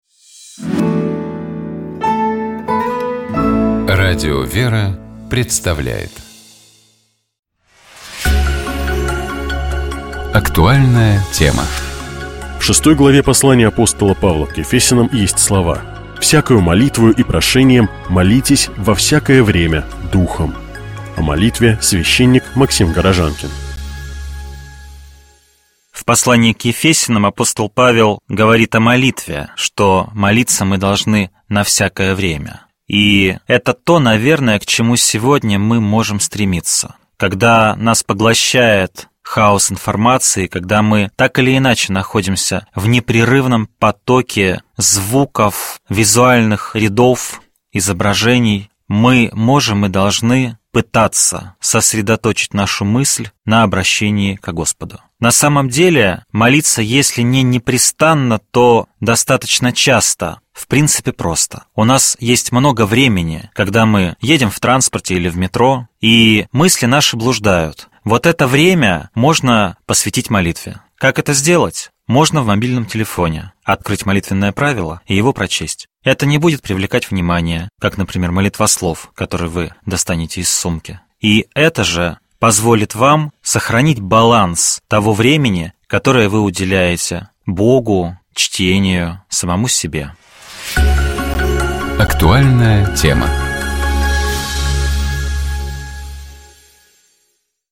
Программа «Семейный час» - это часовая беседа в студии с участием священника. В этой программе поднимаются духовные и нравственные темы, связанные с семейной жизнью, воспитанием детей и отношениями между поколениями.